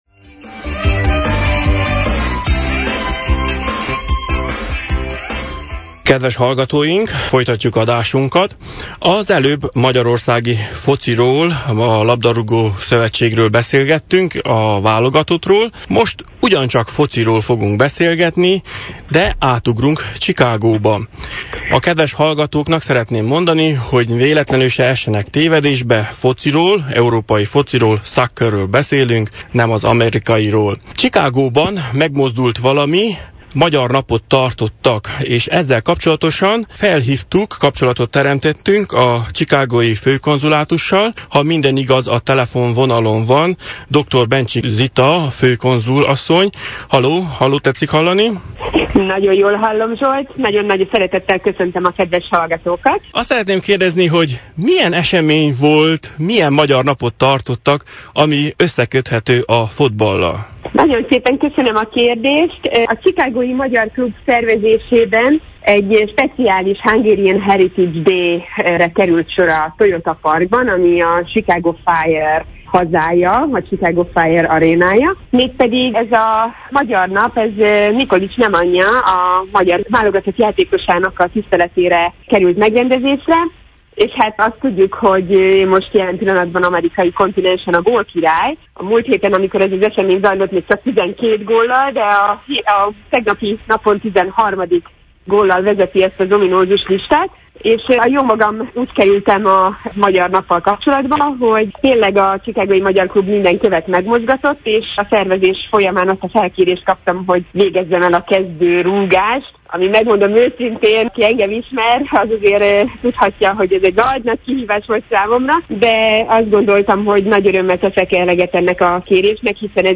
2017. június 18-i adásunkban, egy telefonos interjú segítségével, felvettük a kapcsolatot Magyarország chicagói főkonzuljával Dr. Bencsik Zitával, akivel két témát vitattunk meg.